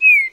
animalia_eastern_blue_1.ogg